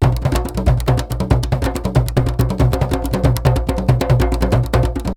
PERC 12.AI.wav